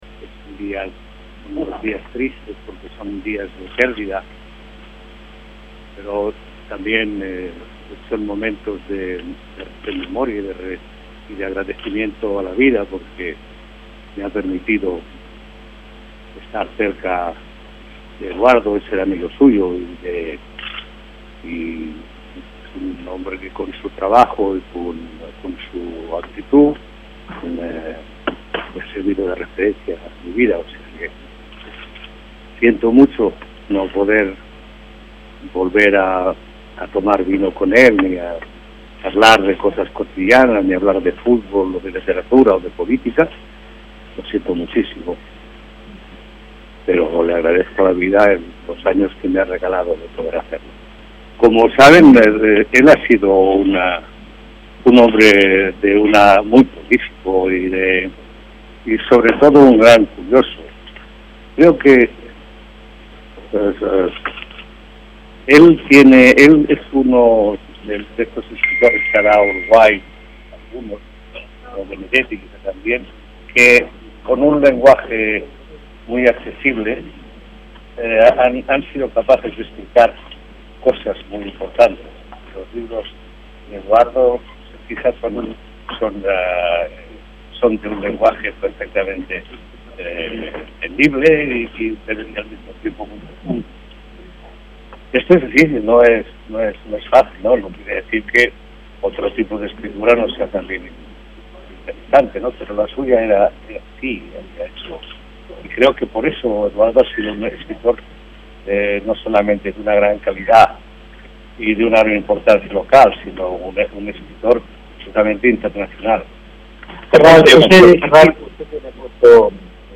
El cantautor español Joan Manuel Serrat concurrió esta tarde al Salón de los Pasos Perdidos del Palacio Legislativo para darle el último adiós a su amigo, el escritor uruguayo Eduardo Galeano, quien falleció este lunes.
Serrat habló de su amigo, Galeano